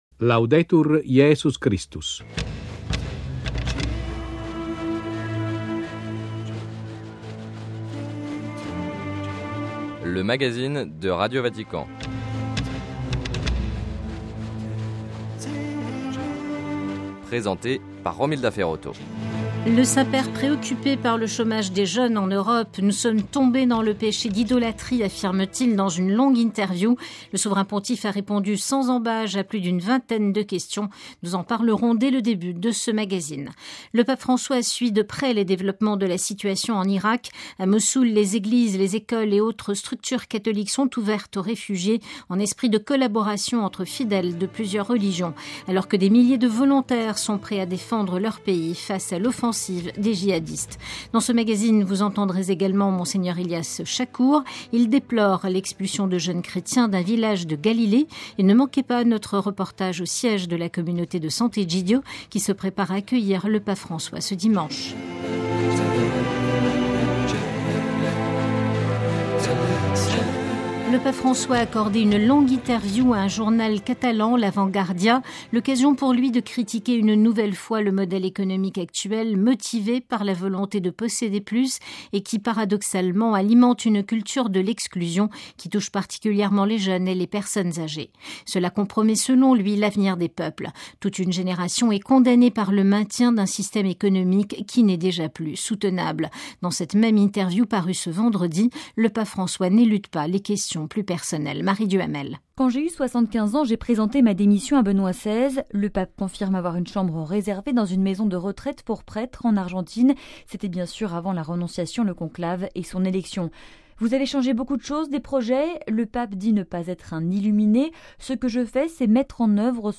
- Reportage au siège de la communauté catholique romaine Sant'Egidio qui se prépare à accueillir le Saint-Père dimanche. - Un prêtre français, Louis-Edouard Cestac, bientôt béatifié.